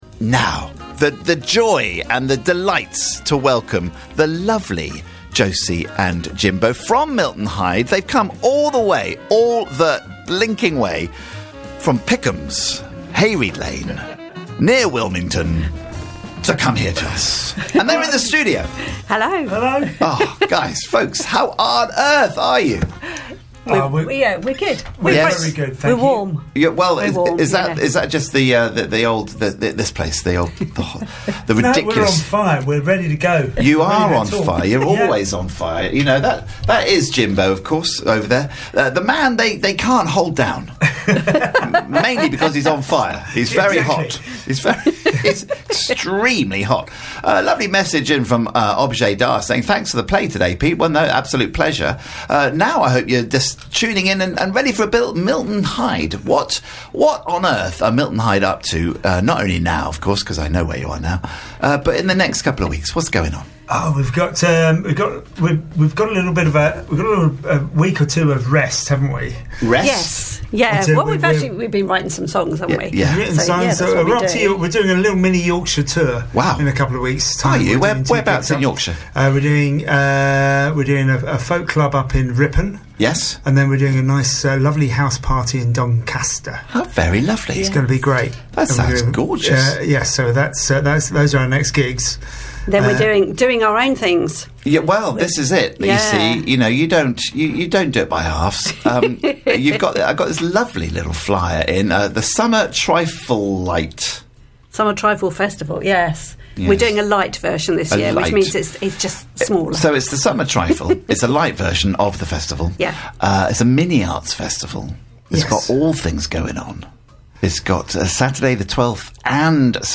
Three tracks played live: